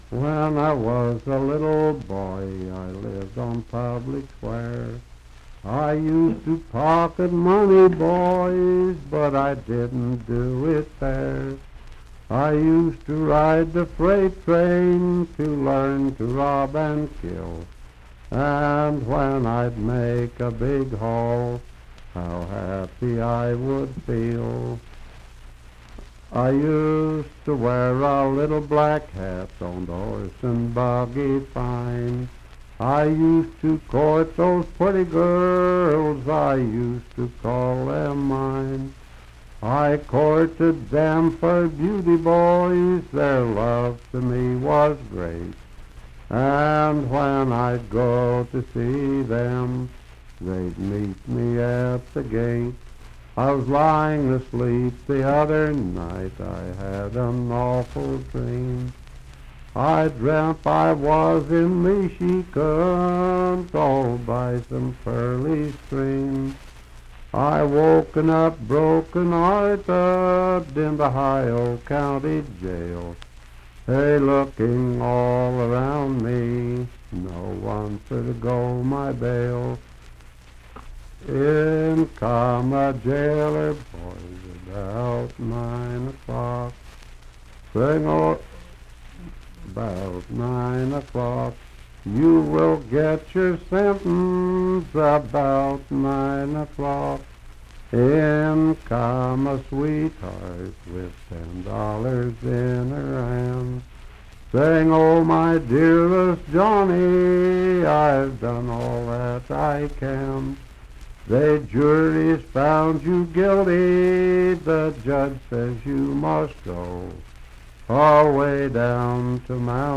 Unaccompanied vocal music
Verse-refrain 5d(4).
Voice (sung)